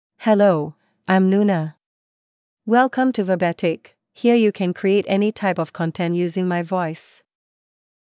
Luna — Female English (Singapore) AI Voice | TTS, Voice Cloning & Video | Verbatik AI
Luna is a female AI voice for English (Singapore).
Voice sample
Luna delivers clear pronunciation with authentic Singapore English intonation, making your content sound professionally produced.
Luna's female voice brings stories to life with natural pacing, emotional range, and authentic Singapore English pronunciation for an engaging listening experience.